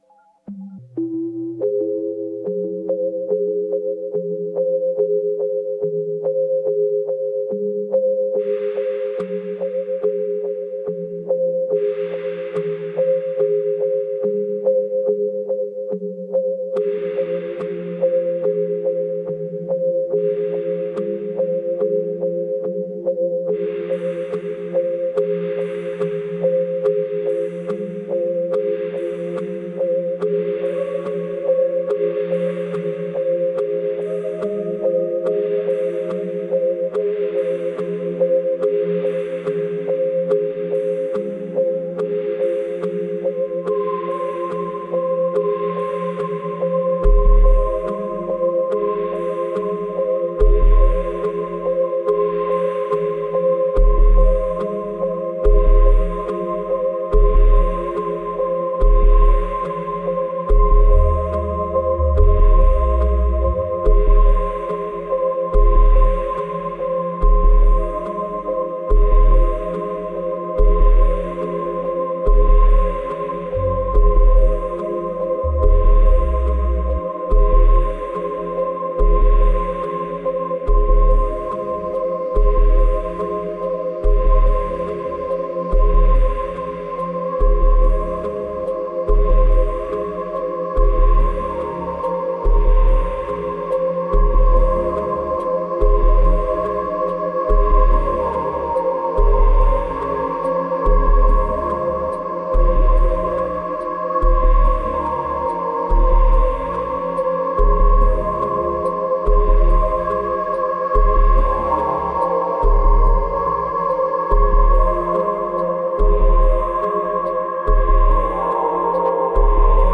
2703📈 - 90%🤔 - 71BPM🔊 - 2016-03-27📅 - 284🌟